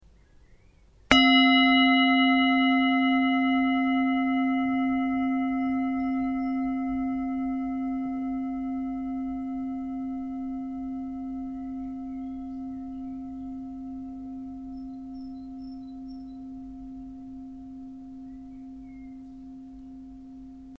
Tibetische Klangschale - HERZSCHALE
Gewicht: 603 g
Grundton: 263,76 Hz
1. Oberton: 705,23 Hz
M72-603g-Klangschale.mp3